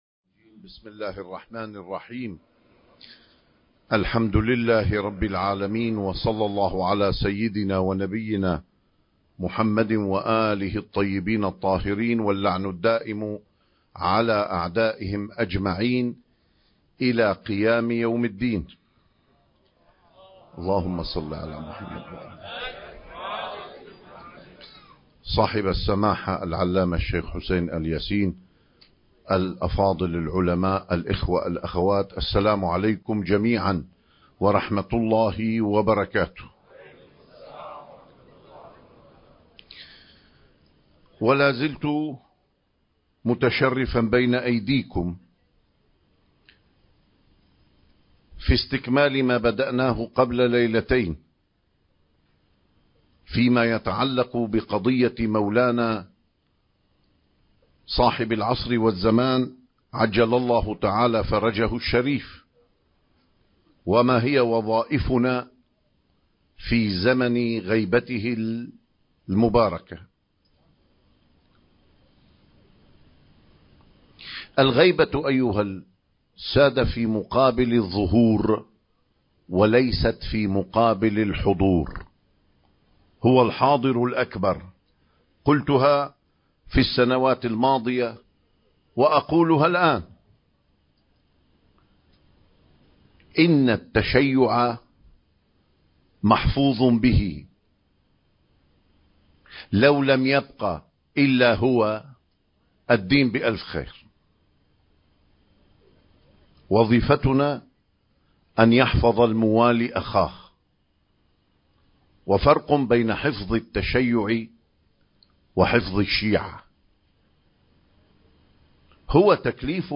الإمام المهدي (عجّل الله فرجه) وأدب الانتظار (1) المكان: مسجد آل ياسين / الكاظمية المقدسة التاريخ: 2025